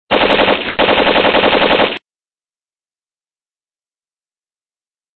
Dzwonki Ogień Karabinu Maszynowego
Kategorie Efekty Dźwiękowe